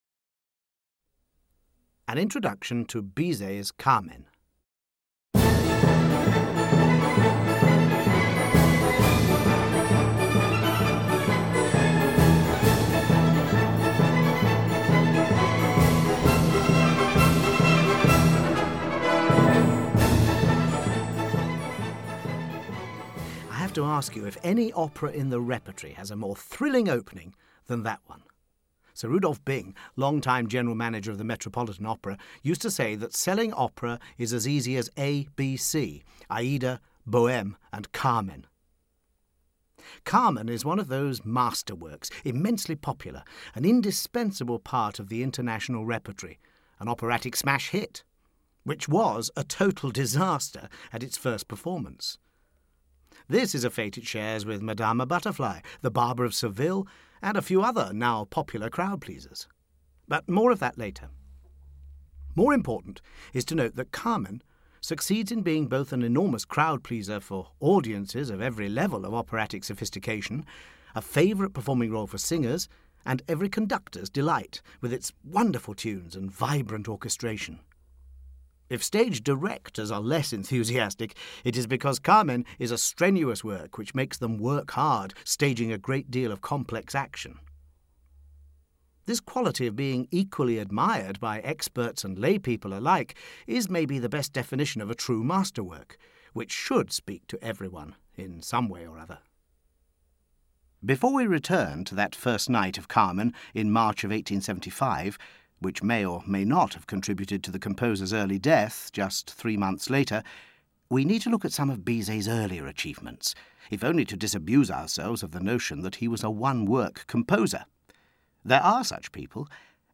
Audio knihaOpera Explained – Carmen (EN)
Ukázka z knihy
As always in the Opera Explained series, the music itself plays an integral role.